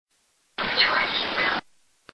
Harney Mansion EVP
The raw but slightly amplified EVP